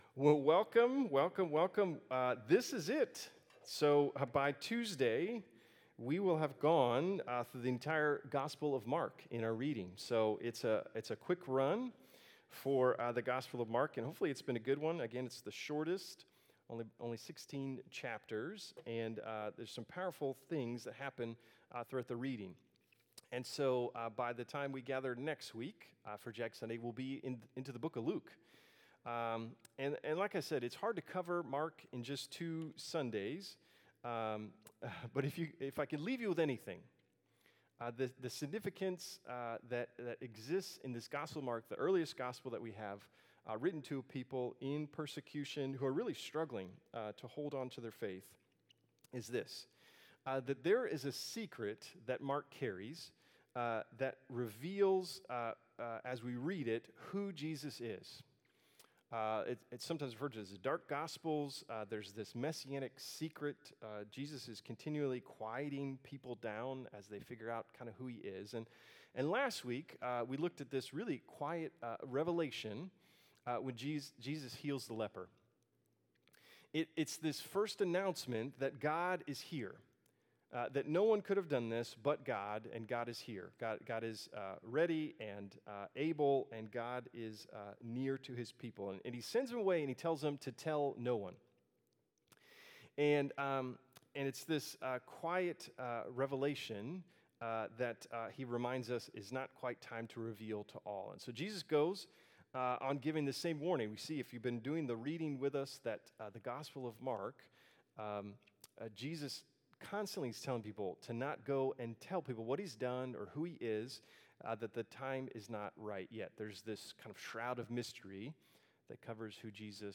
Sermons | Bridge City Church